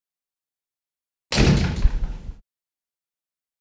دانلود صدای باز و بسته شدن در زندان 1 از ساعد نیوز با لینک مستقیم و کیفیت بالا
جلوه های صوتی